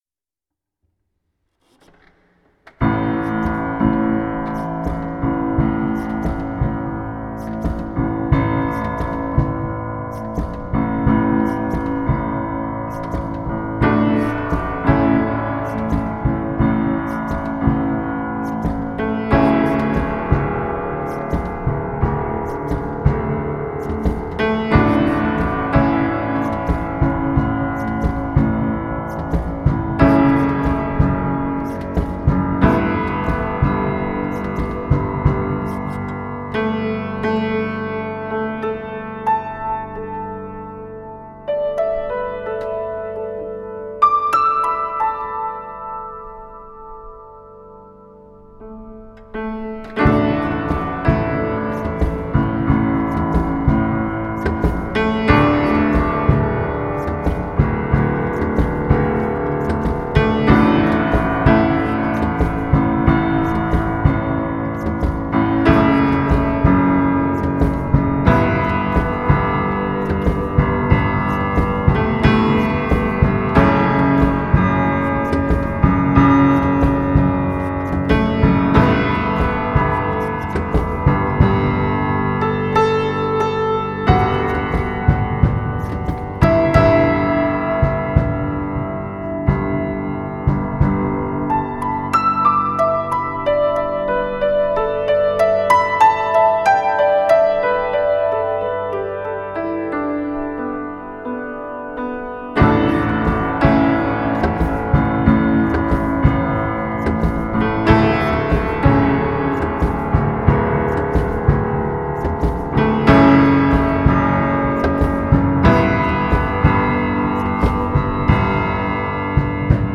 Je partage avec vous une captation improvisée ce matin sur le piano éprouvé et généreux du studio de répé 7.2 à Trempolino puis mixée à la maison dans la soirée.